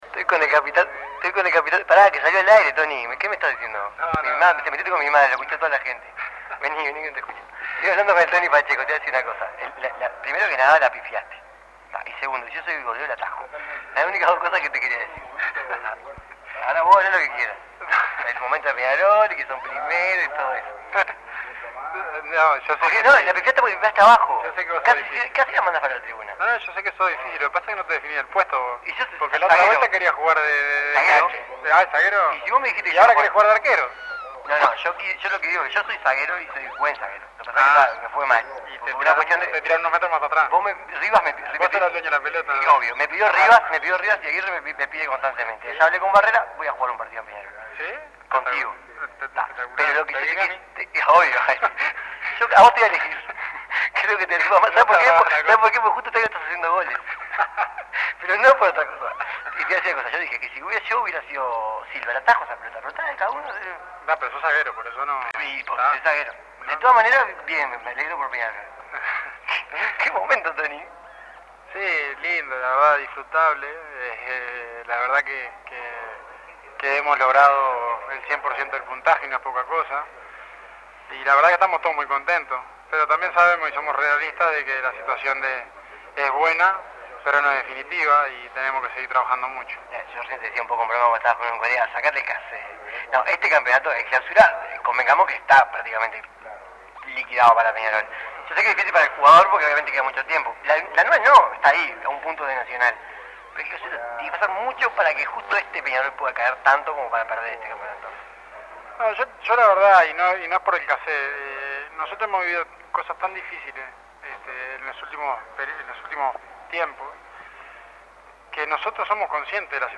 El capitán de Peñarol, Antonio Pacheco, habló con 13 a 0 despues de la victoria ante Defensor Sporting. Además de destacar el momento futbolístico que vive Peñarol resaltó entre lagrimas la emoción que siente cuando la hinchada lo ovaciona.